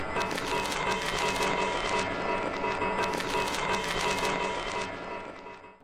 Genre: IDM, Abstract, Experimental.